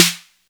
SouthSide Snare Roll Pattern (7).wav